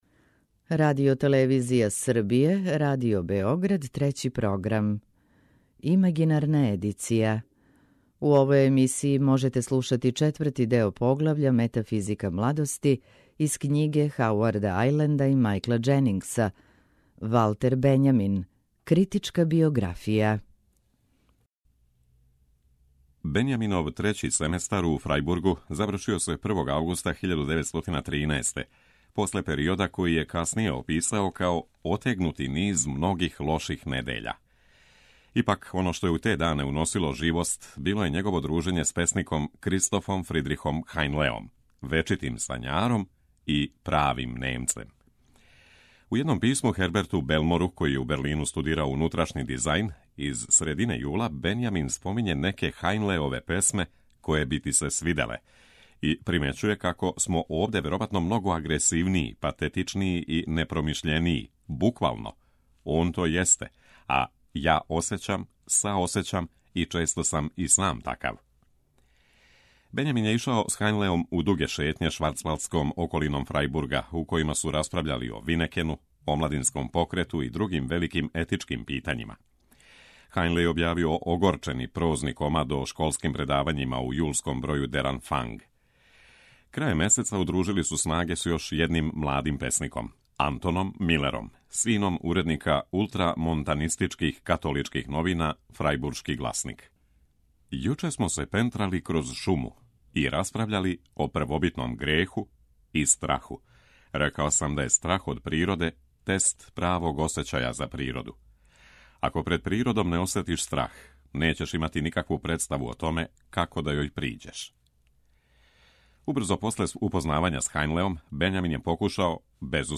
Прва говорна емисија сваке вечери од понедељка до петка.